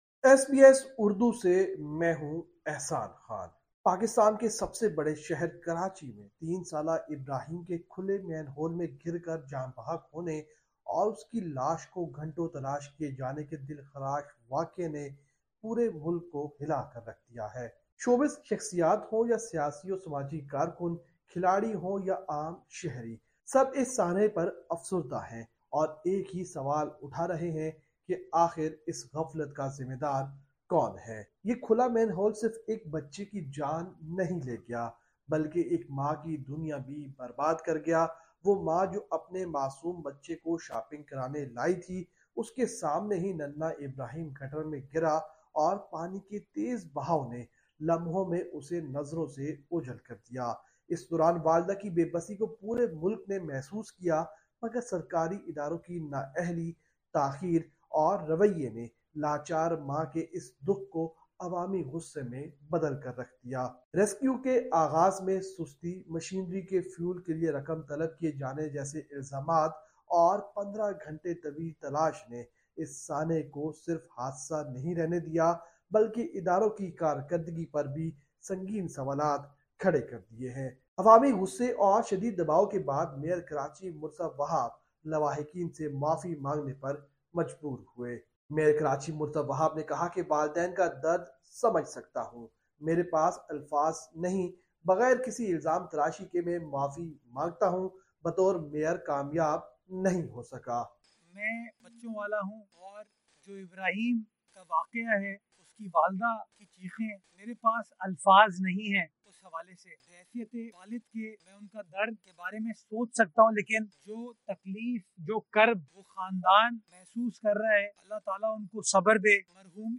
ایس بی ایس اردو کے لیے یہ رپورٹ پاکستان سے